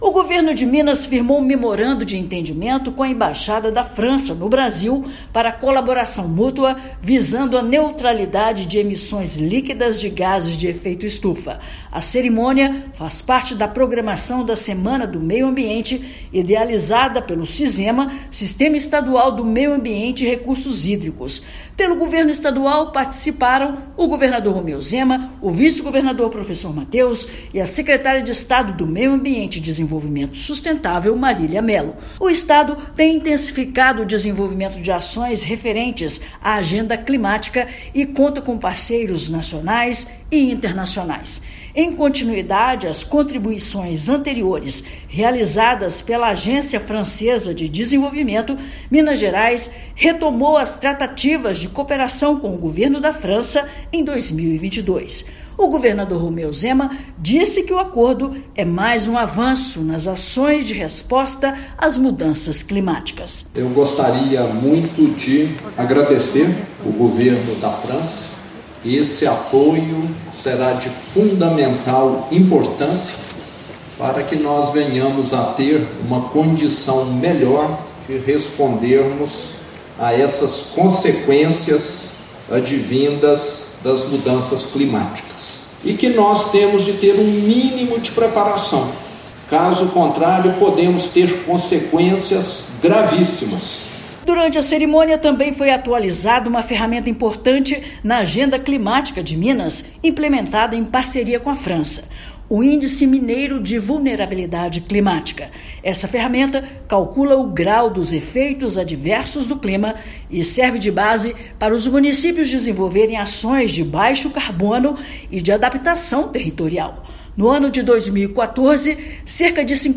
Também foi apresentada a atualização do Índice Mineiro de Vulnerabilidade Climática (IMVC), ferramenta que serve de apoio aos municípios para o desenvolvimento de ações de adaptação territorial. Ouça a matéria de rádio.